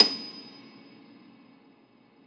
53e-pno27-D6.wav